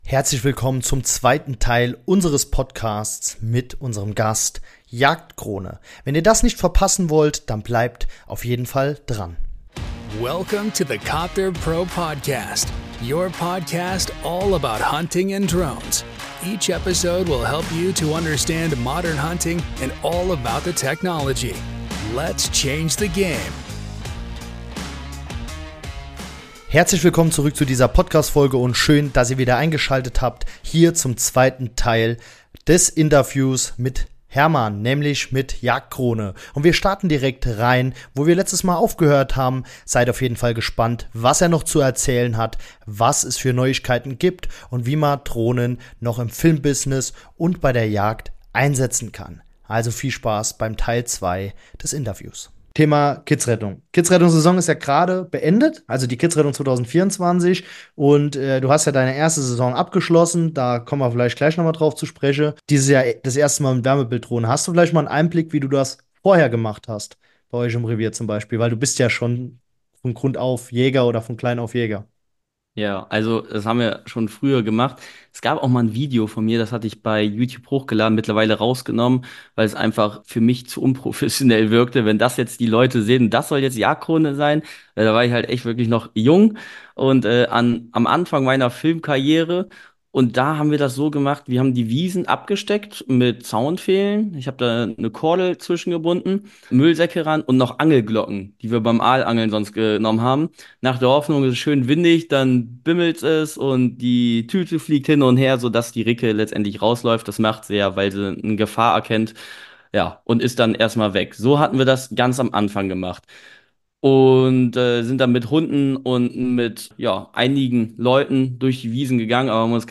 Interview mit Jagdkrone - Kitzrettung, Nachsuche Unterstützung und Jagfilme [2/2] ~ Copterpro Podcast: Alles zum Thema Drohnen und moderne Jagd Podcast